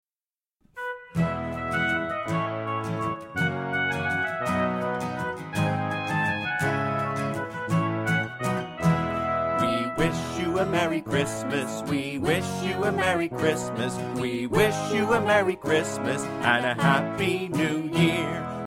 Vocal Song
Piano Solo